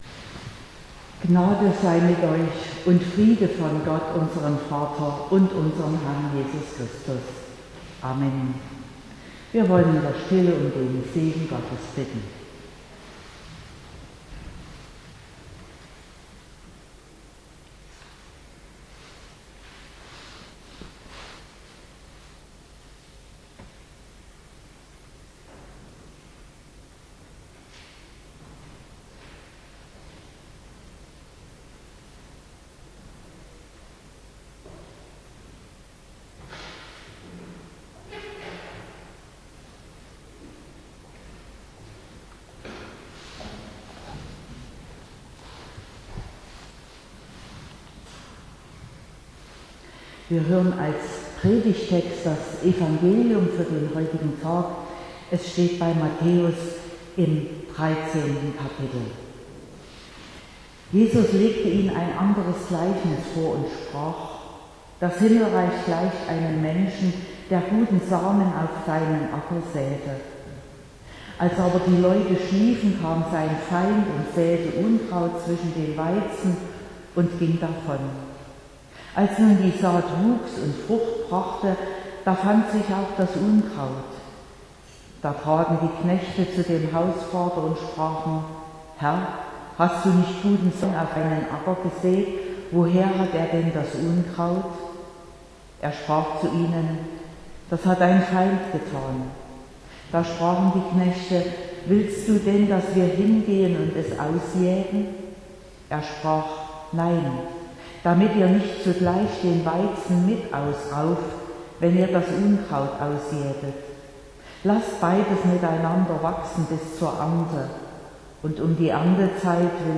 31.12.2021 – Gottesdienst
Predigt und Aufzeichnungen